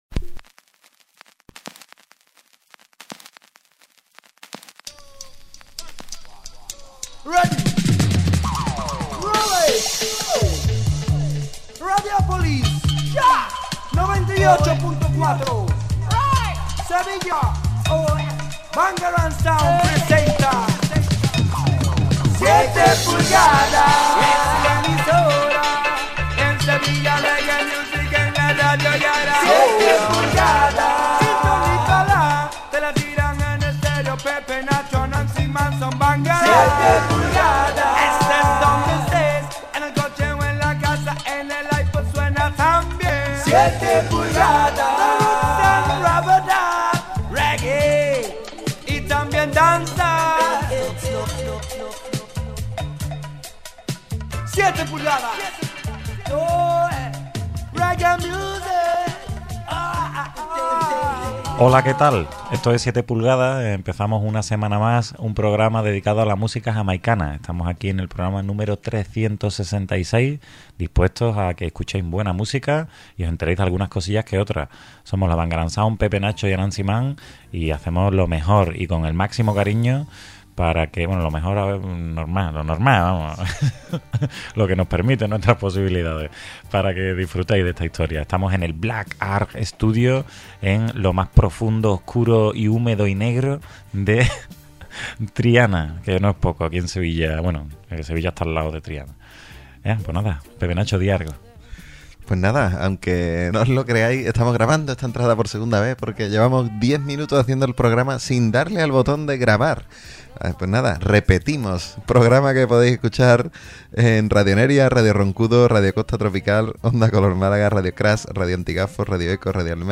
Presentado y dirigido por la Bangarang Sound y grabado en Black Arggh Studio.